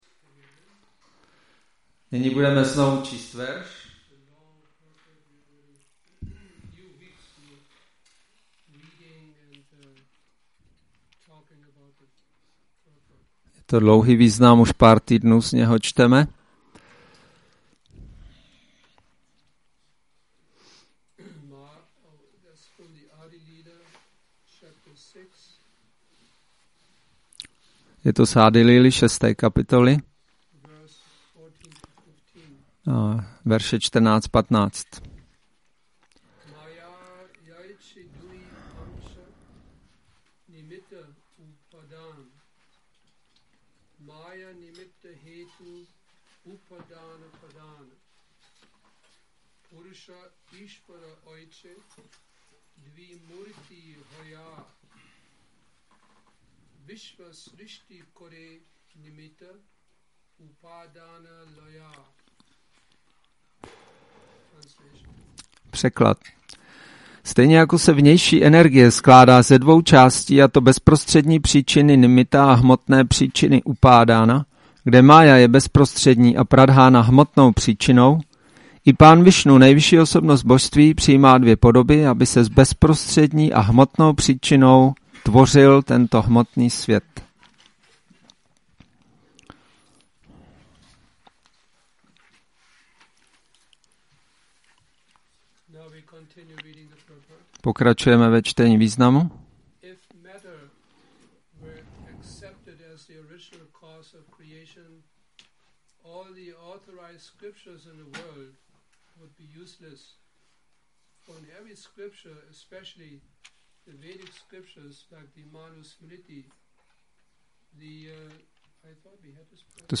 Přednáška CC-ADI-6.14